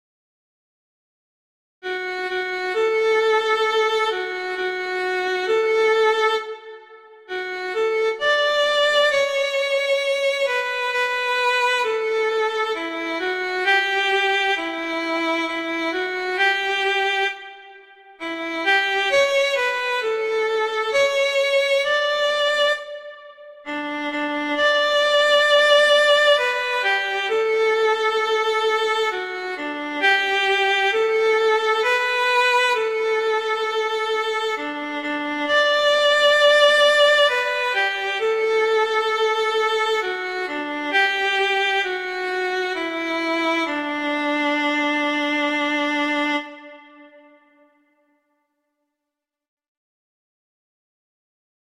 arrangements for violin solo
classical, children